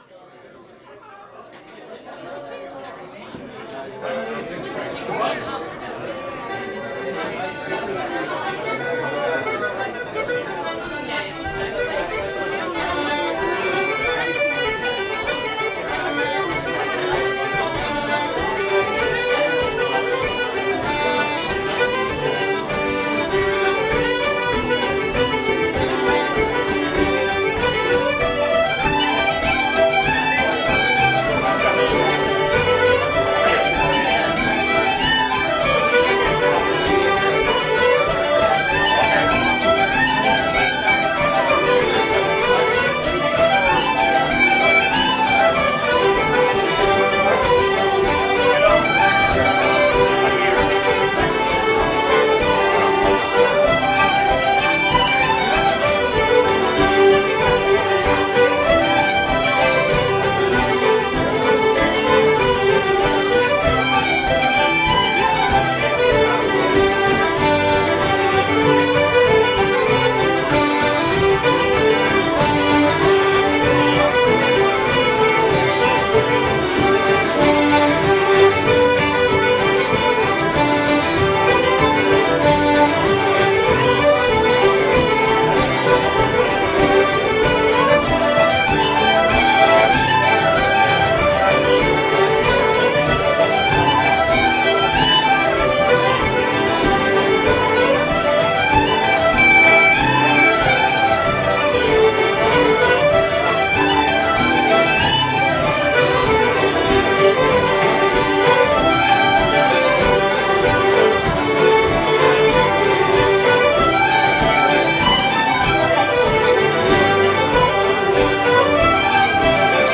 Excerpt from a Pub Session at Stack's Pub during Catskills Irish Arts Week. (Audio)